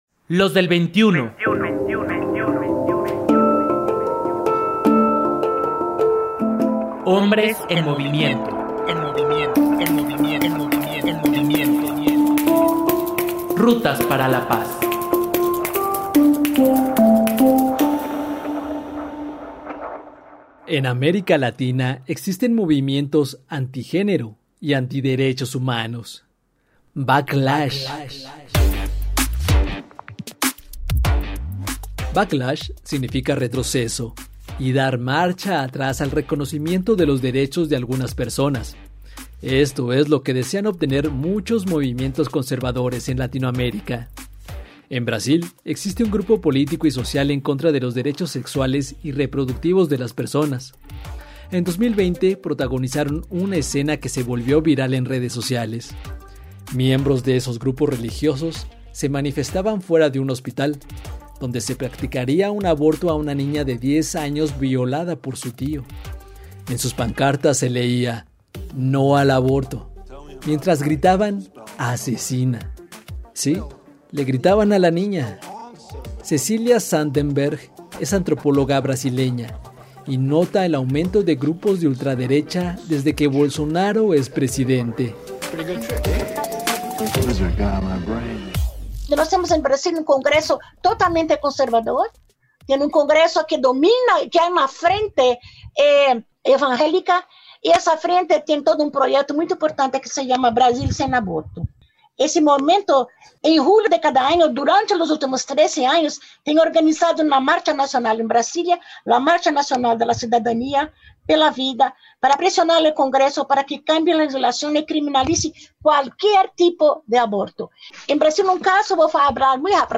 Sin embargo, los participantes de esta mesa virtual coinciden en que “no todo es pesimista” pues la academia y el activismo continúan trabajando, y así seguirán, para que se garanticen los derechos de todas las personas.